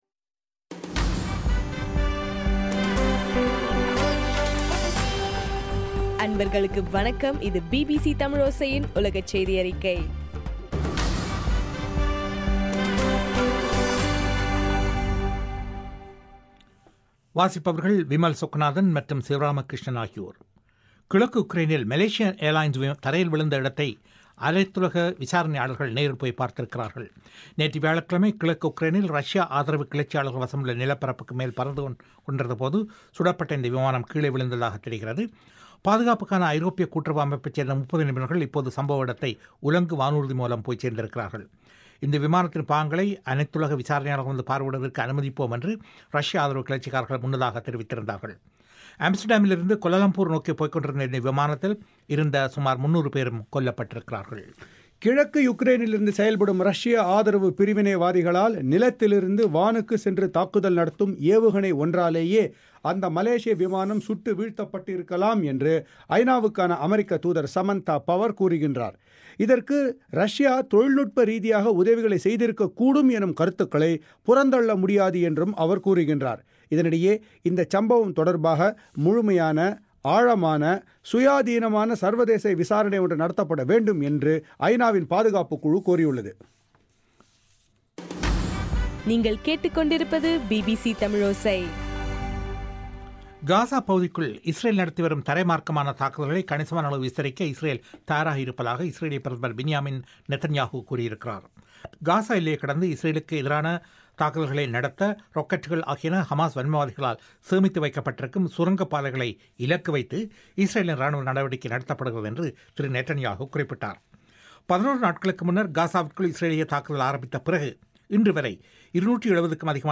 ஜூலை 18 பிபிசியின் உலகச் செய்திகள்